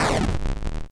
Enemy_Dies.wav